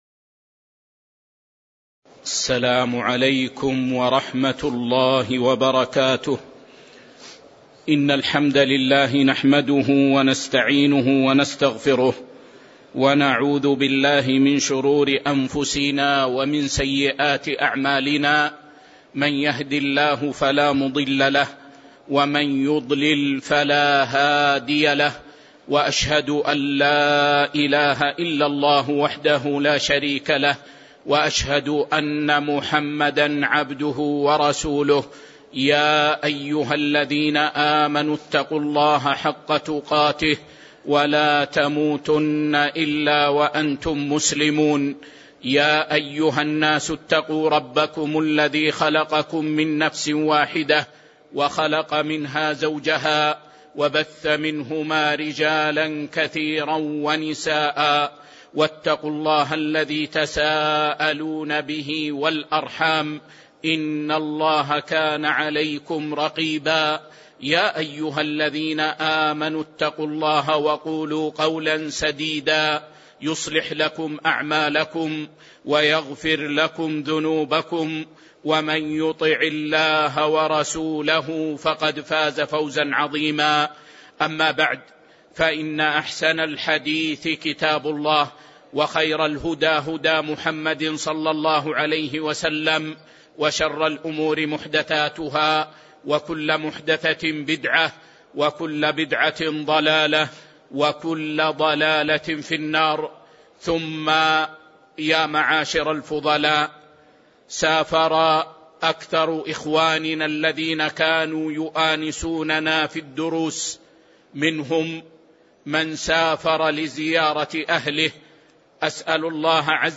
تاريخ النشر ٧ ذو الحجة ١٤٤٣ هـ المكان: المسجد النبوي الشيخ